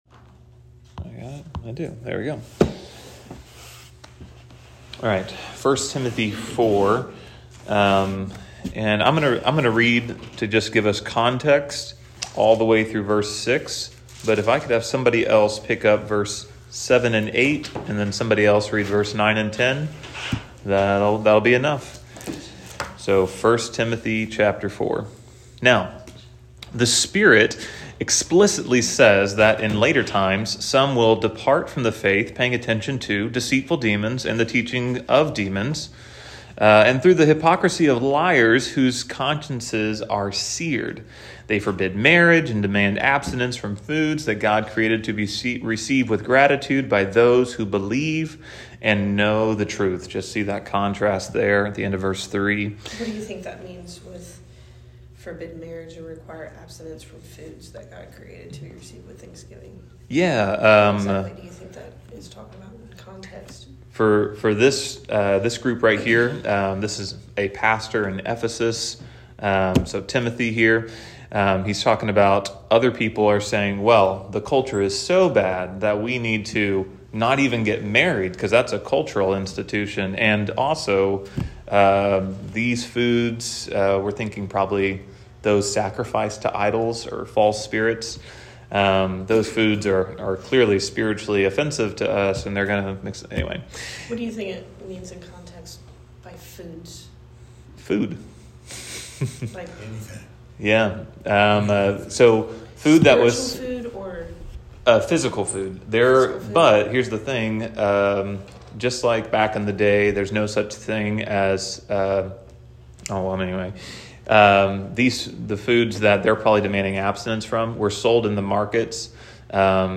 -Wednesday Morning Bible Study - 1 Tim. 4:7-16